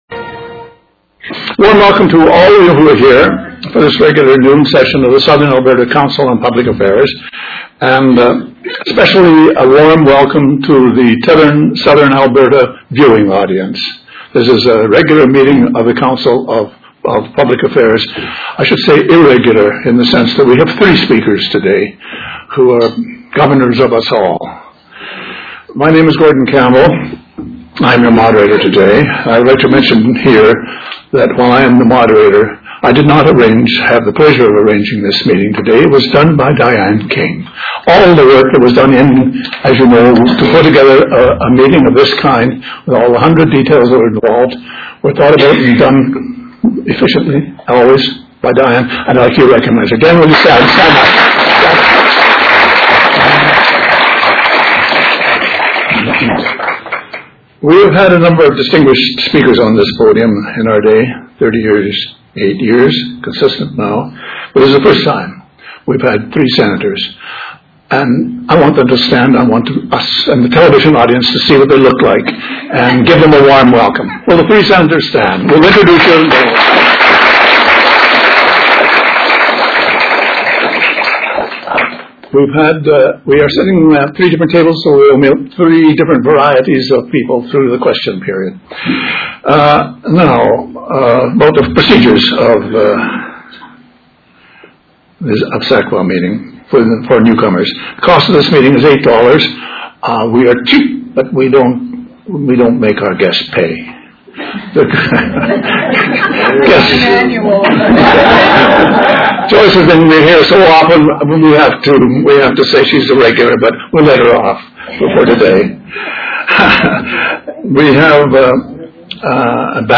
For the first time in the history of SACPA, we are pleased to inform you that our speakers for Thursday,, October 12, 2006 will be three members of the Canadian Senate: SENATOR TOMMY BANKS (Edmonton), SENATOR JOYCE FAIRBAIRN(Lethbridge) AND SENATOR DAN HAYS (Calgary). SENATOR BANKS will address current issues regarding the environment with a special focus on water issues. SENATOR FAIRBAIRN will focus her message on agriculture but will also address literacy. SENATOR HAYS will speak on Senate reform.